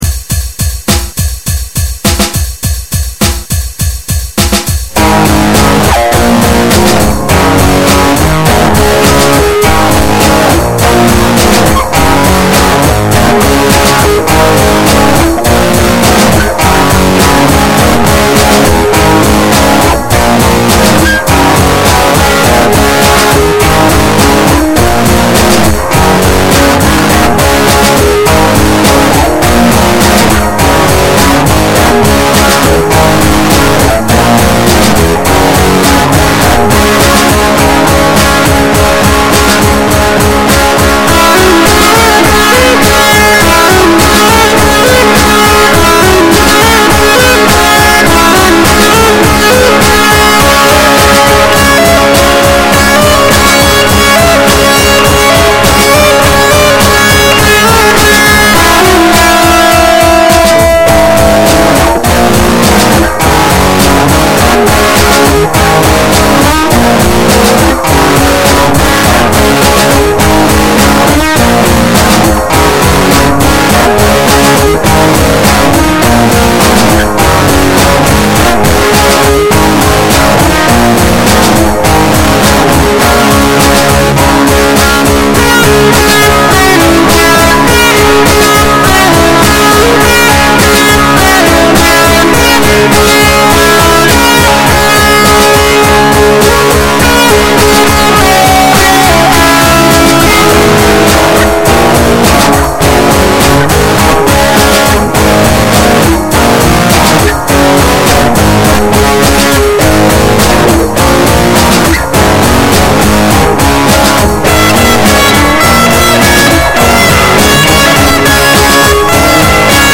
1. An Ibanez ArtCore AF75 hollow-body electric guitar, plugged into
4. Using Hammerhead Rhythm Station (run via Wine) for drums
I know the mixing is terrible and there’s distortion. I know I hit some sour notes and my grasp of Key is, um, fragile.